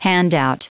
Prononciation du mot handout en anglais (fichier audio)
Prononciation du mot : handout